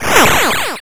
bfxr_laseractivate.wav